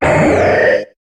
Cri d'Avaltout dans Pokémon HOME.